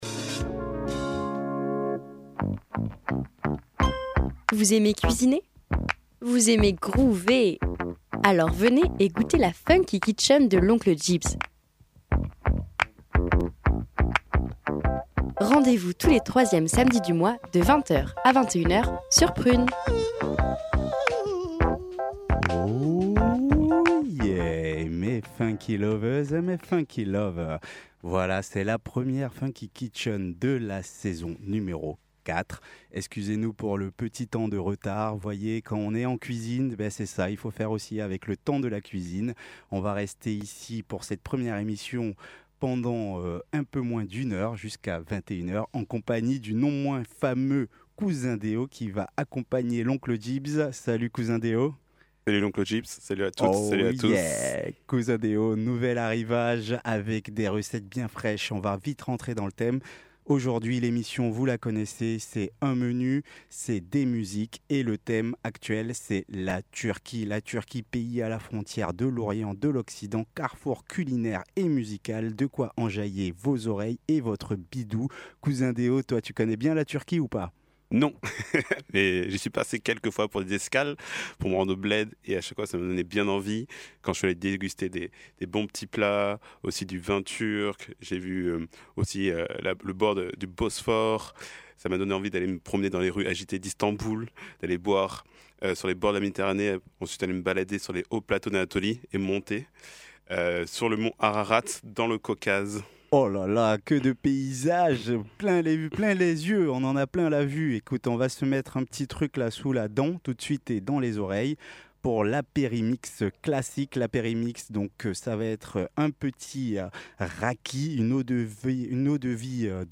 Plaisir des oreilles aux délicieuses saveurs turques pour le premier menu de la saison!